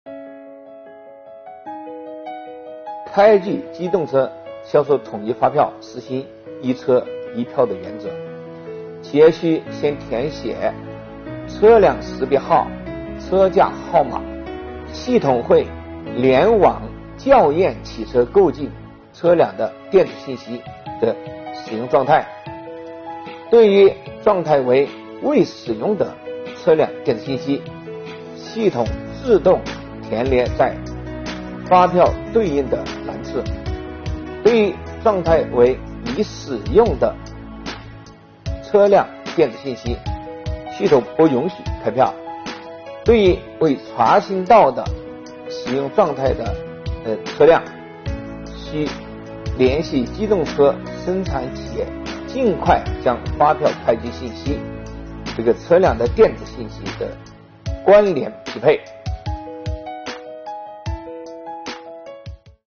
近日，国家税务总局推出“税务讲堂”课程，国家税务总局货物和劳务税司副司长张卫详细解读《办法》相关政策规定。